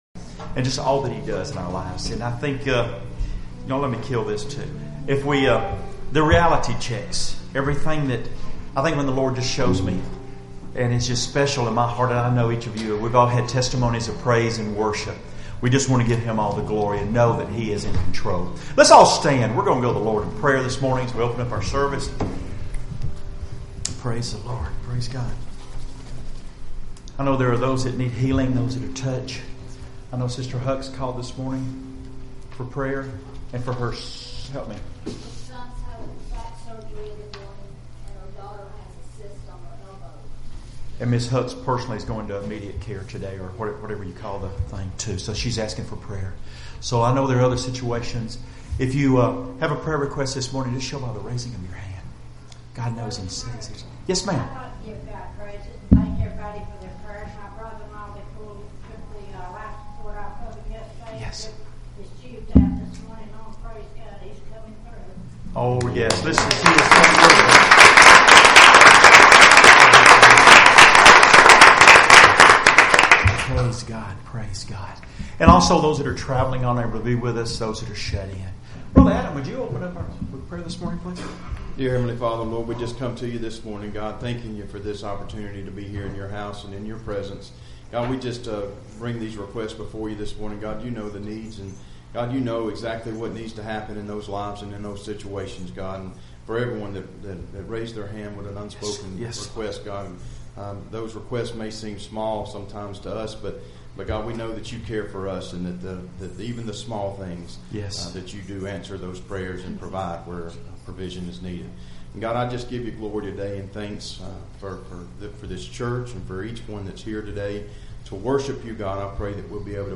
Homecoming Service 2018